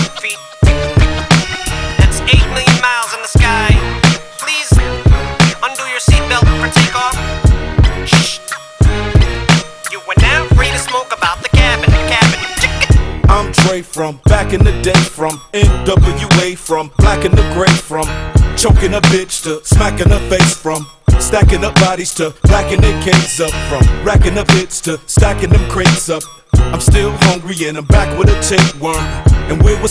es un tono de celular que pertenece a la categoría Hiphop